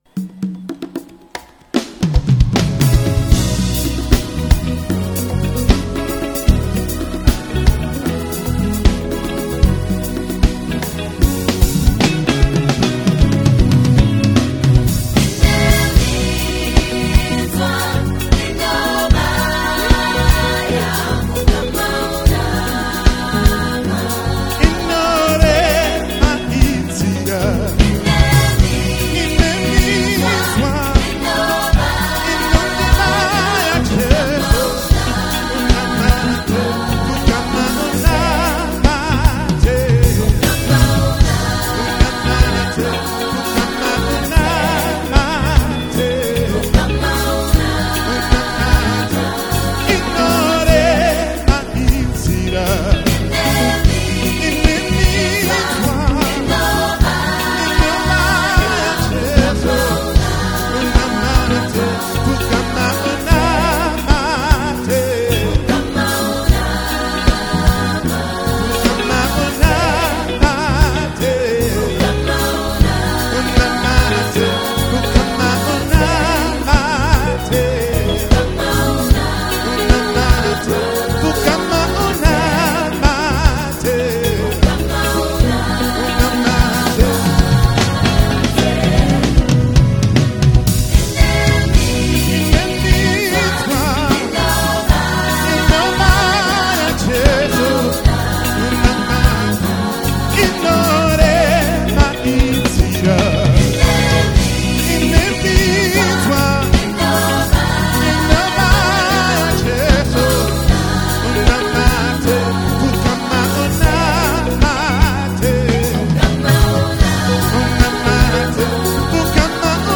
South African Gospel
Genre: Gospel/Christian.